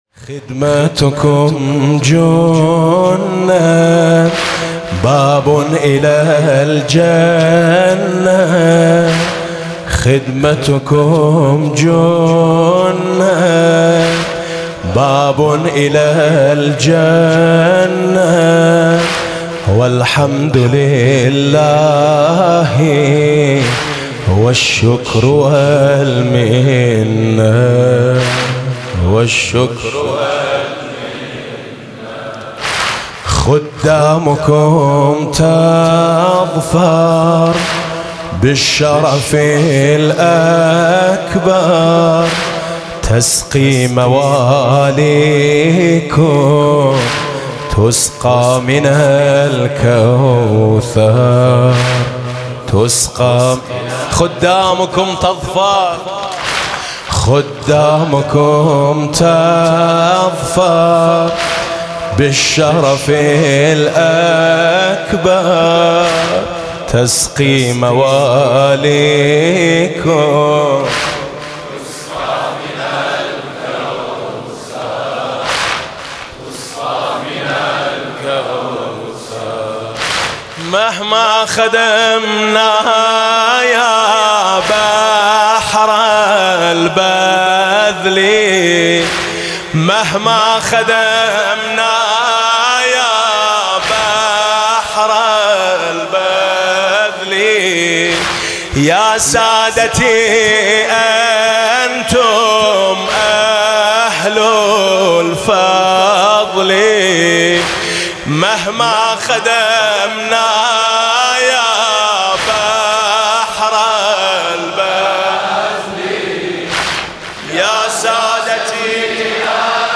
مداحی عربی از زبان خادمان مواکب حسینی در اربعین
میثم مطیعی